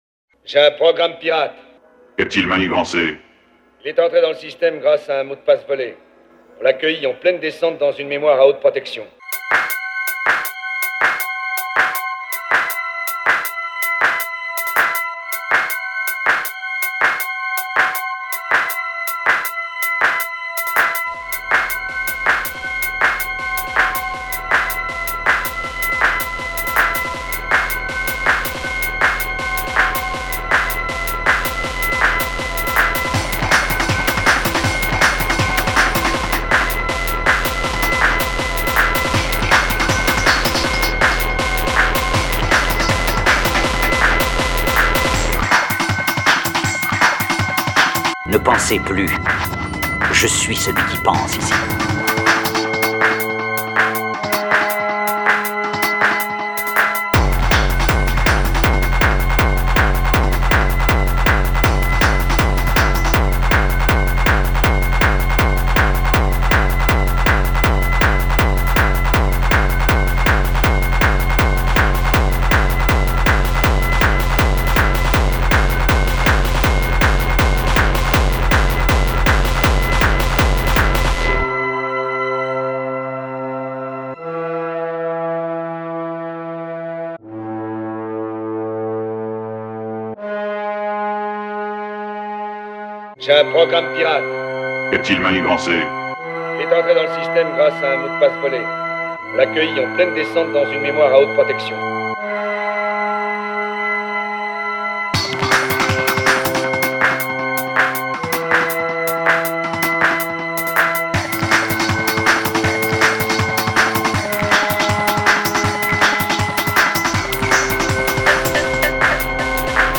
dance/electronic
Techno
Hardcore
Breaks & beats